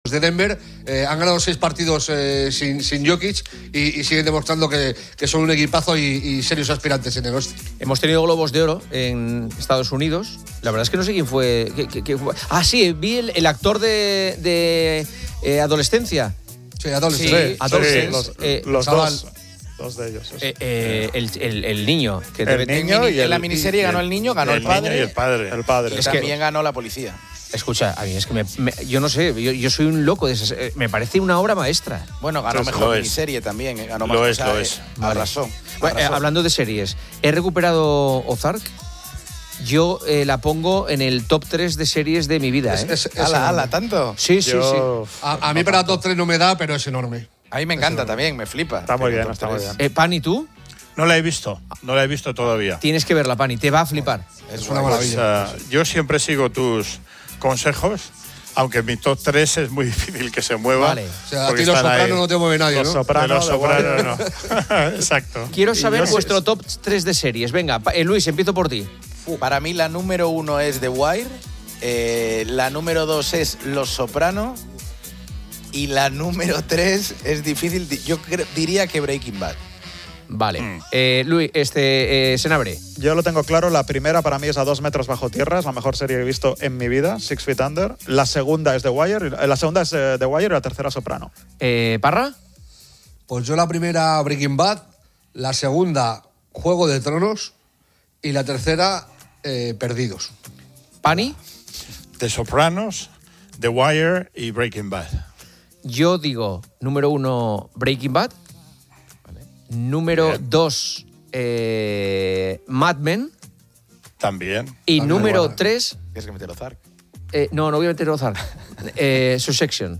Los participantes debaten sobre sus series favoritas, mencionando títulos como 'Ozark', 'The Wire', 'Los Soprano', 'Breaking Bad', 'A 2 metros bajo tierra', 'Juego de Tronos', 'Perdidos' y 'Mad Men'.